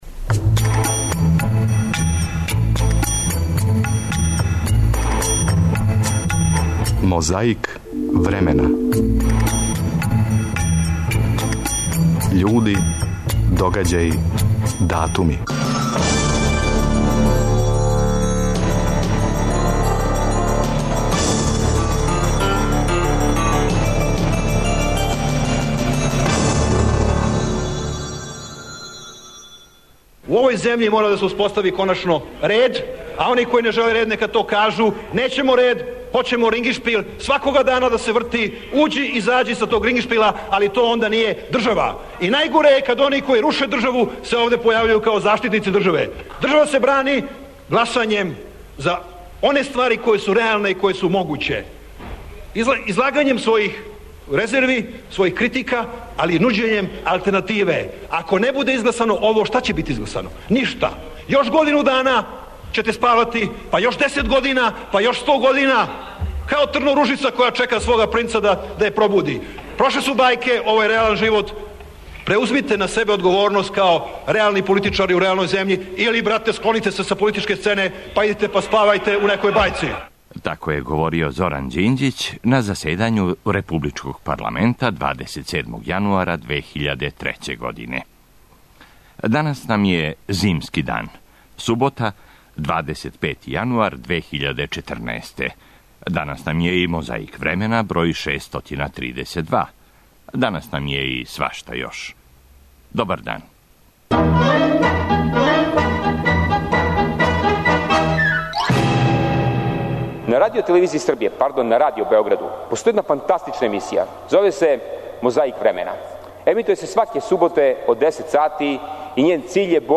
Борба нас враћа у 2003. годину, датум 27. јануар. Догађај: заседање републичког парламента. Говорио: Зоран Ђинђић.
Такође, некад било али, захваљујући техници, можемо да чујемо и како се говорило на Двадесетој седници ЦК СКЈ 1. фебруара 1989.